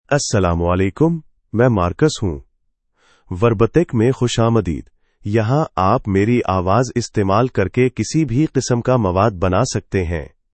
MarcusMale Urdu AI voice
Marcus is a male AI voice for Urdu (India).
Voice sample
Male
Marcus delivers clear pronunciation with authentic India Urdu intonation, making your content sound professionally produced.